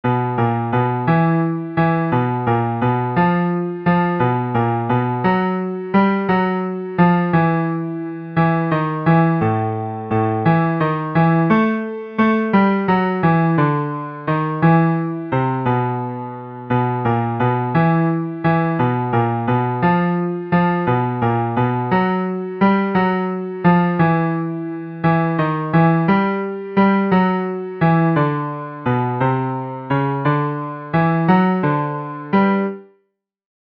Le chœur des invités
Basses
elle_danse_basses.mp3